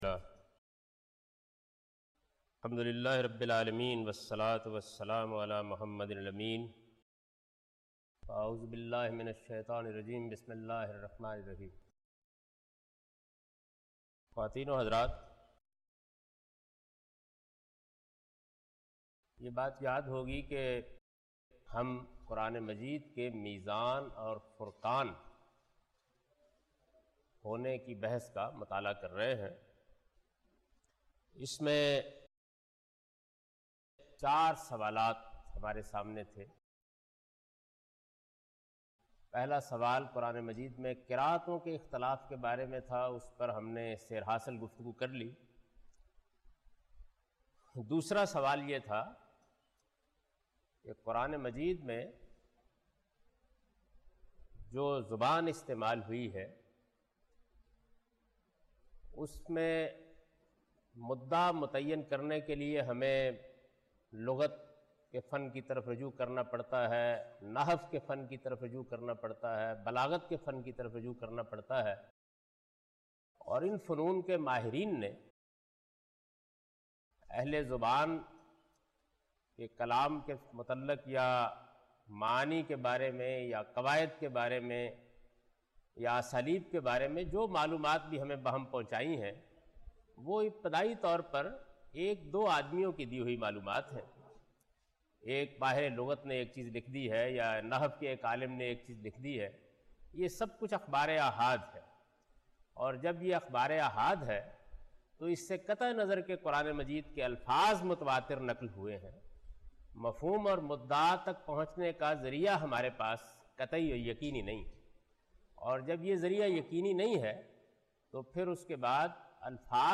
A comprehensive course on Islam, wherein Javed Ahmad Ghamidi teaches his book ‘Meezan’.